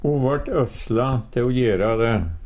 DIALEKTORD PÅ NORMERT NORSK øsle narre, overtale Infinitiv Presens Preteritum Perfektum Eksempel på bruk Ho vart øsla te o gjera de.